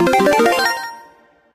2x_gatcha_double_01.ogg